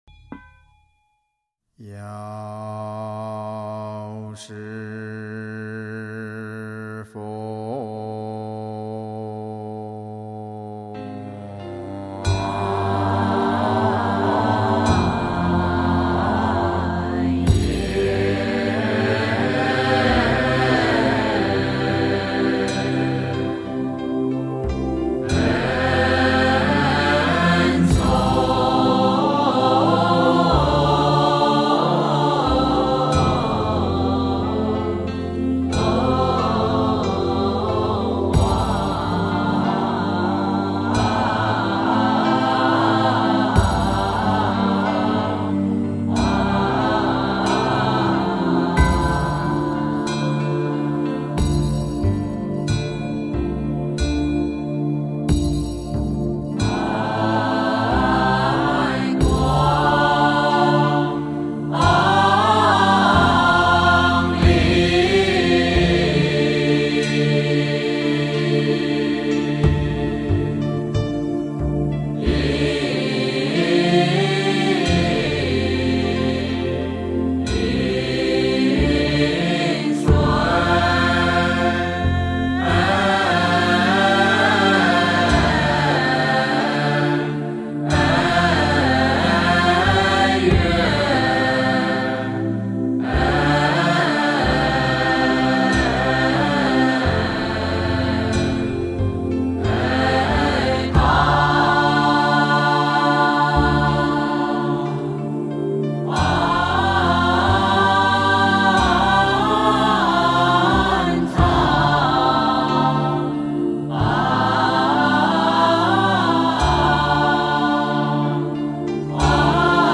药师赞--佛光山梵呗团 经忏 药师赞--佛光山梵呗团 点我： 标签: 佛音 经忏 佛教音乐 返回列表 上一篇： 观音佛号--如是我闻 下一篇： 遥唤根本上师--未知 相关文章 净土文--佛教唱颂编 净土文--佛教唱颂编...